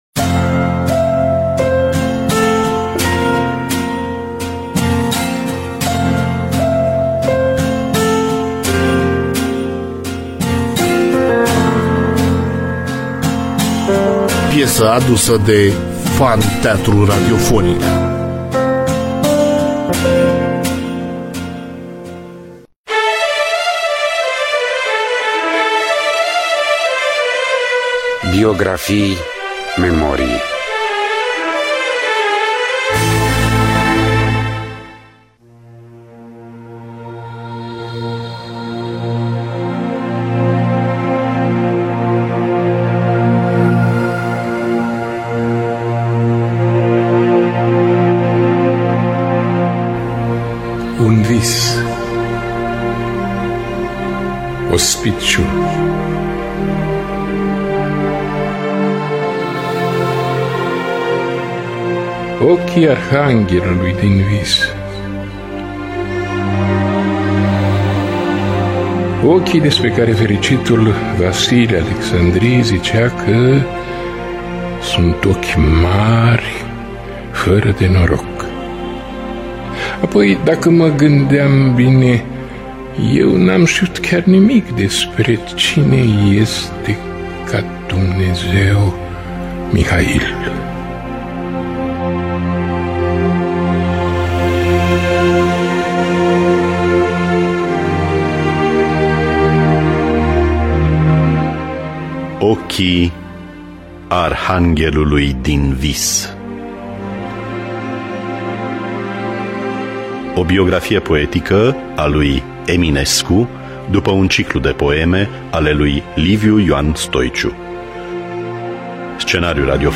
Biografii, Memorii: Ochii Arhanghelului Din Vis (2007) – Teatru Radiofonic Online